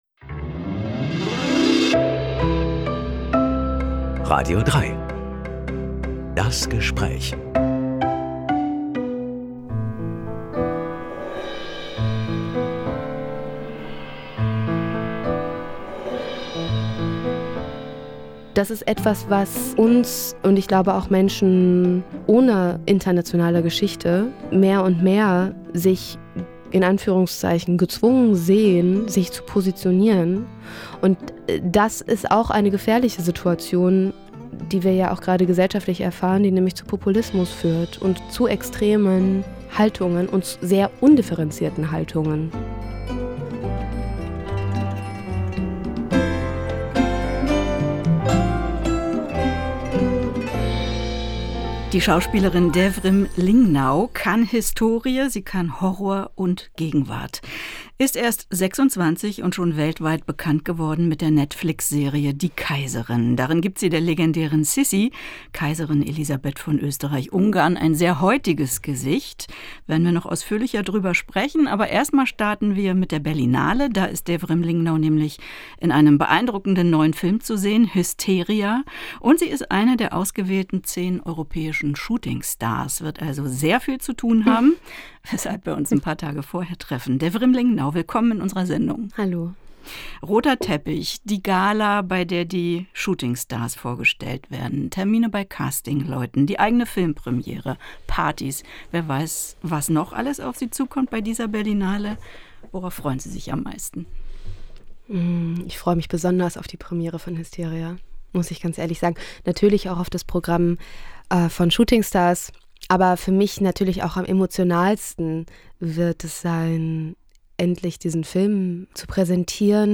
Reden mit einem Menschen.